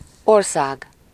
Ääntäminen
IPA : [ˈkʌntri]